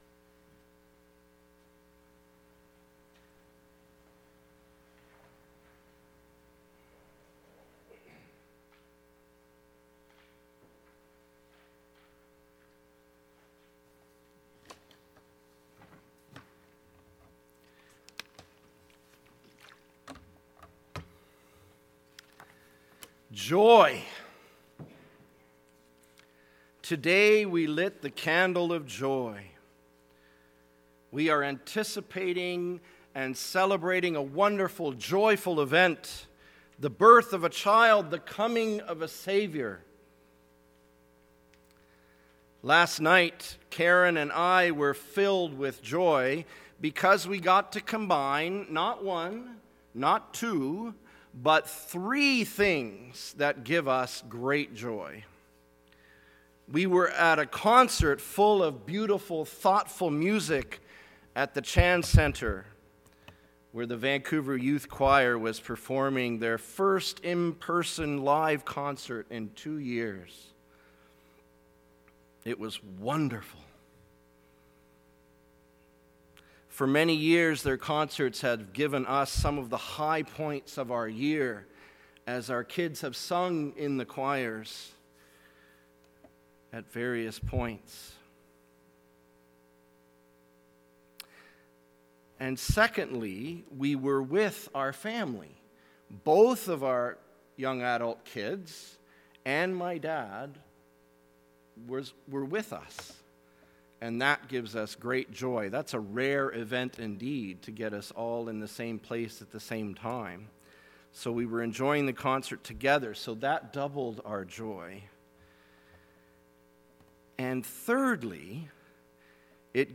December 12, 2021 Sermon - How much is enough?